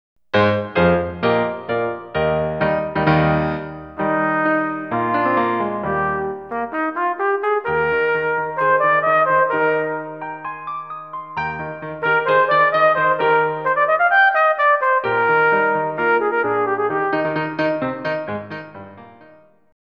CSIS performances with accompaniment